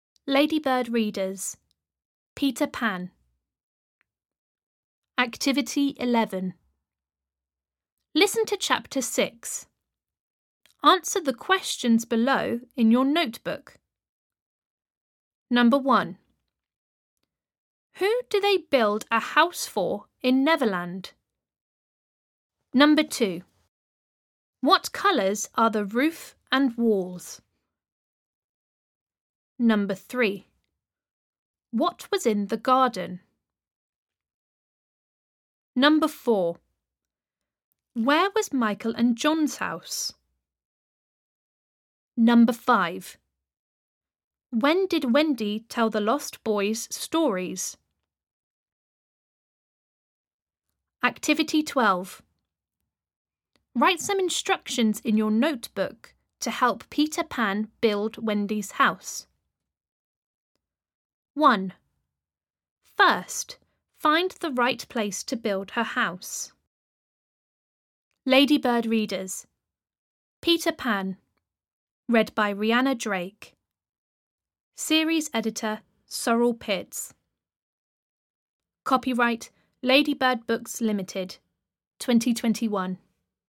Audio UK